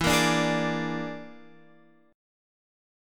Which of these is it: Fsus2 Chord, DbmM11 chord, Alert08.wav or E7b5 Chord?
E7b5 Chord